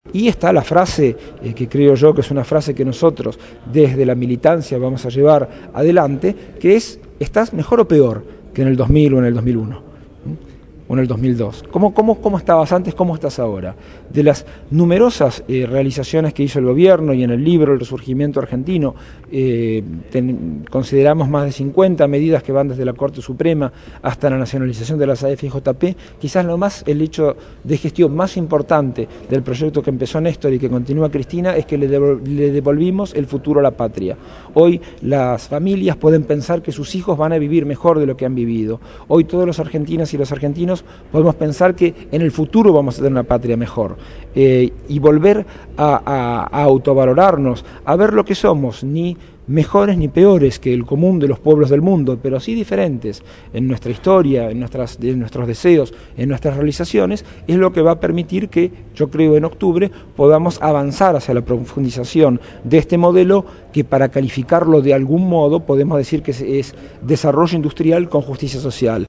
Producción y entrevista